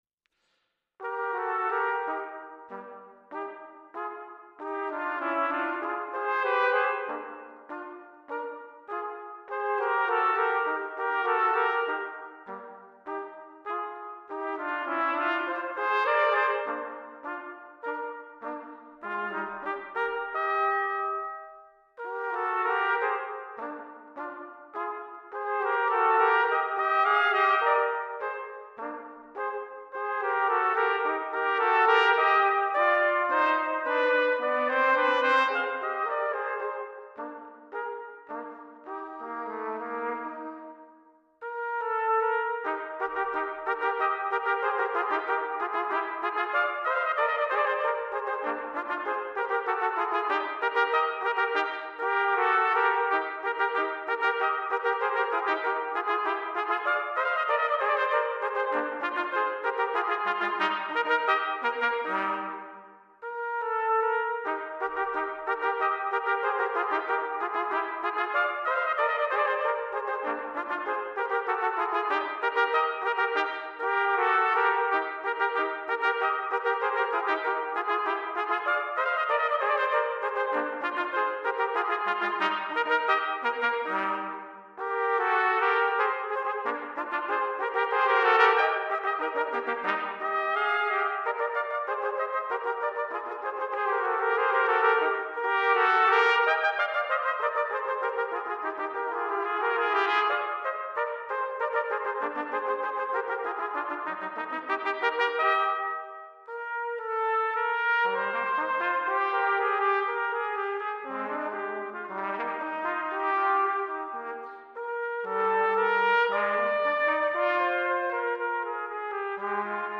68 Duos pour Cornet & instruments SIb.
Ce cd a été enregistré (et retravaillé par ordinateur pour lui assurer un grand confort d’écoute) dans un esprit pédagogique (deux pistes séparées) et historique.